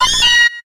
Grito de Meowth.ogg
Grito_de_Meowth.ogg.mp3